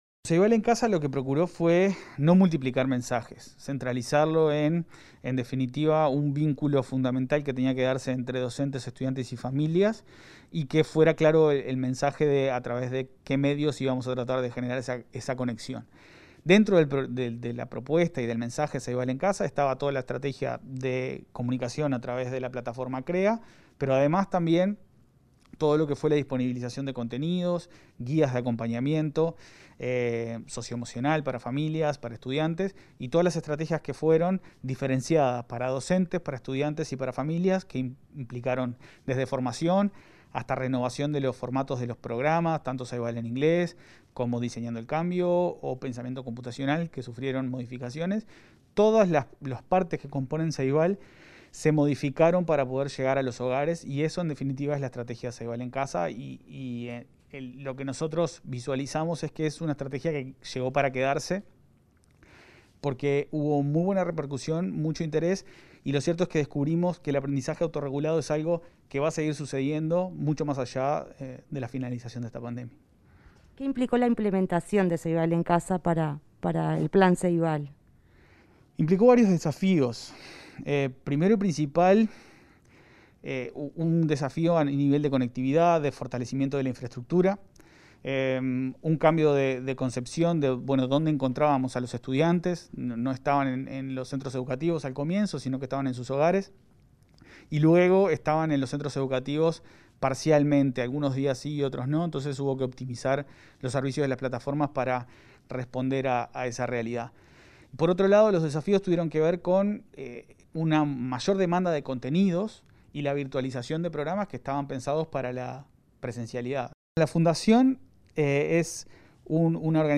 Entrevista al presidente del Plan Ceibal, Leandro Folgar.